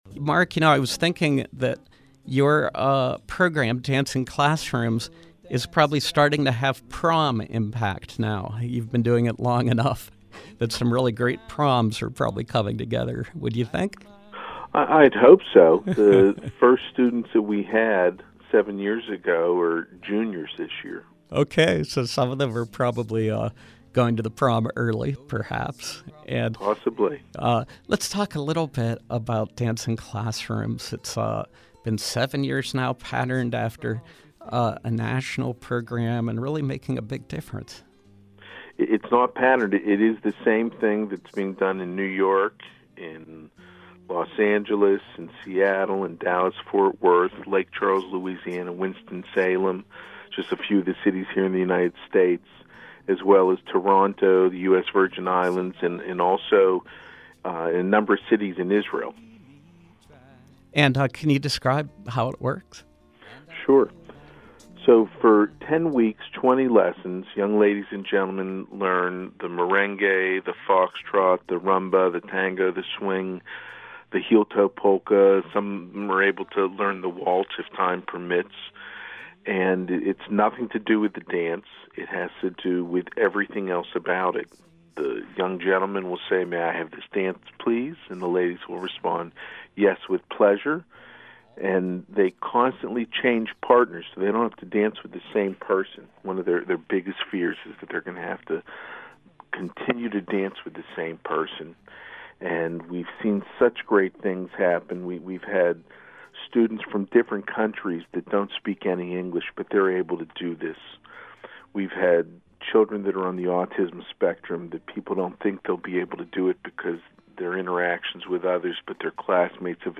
Interview: Dancing Classrooms Pittsburgh, “Colors of the Rainbow”